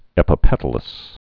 (ĕpə-pĕtl-əs)